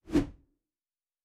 Whoosh 11_1.wav